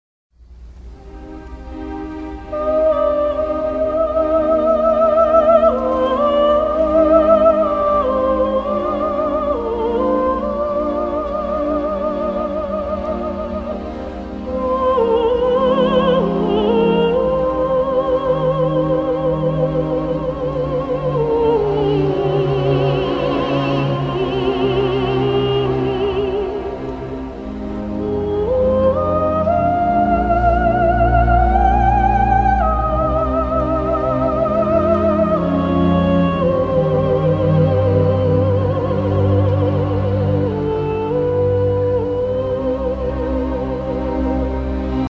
Zang
Hallo daar, ik ben de zangstem!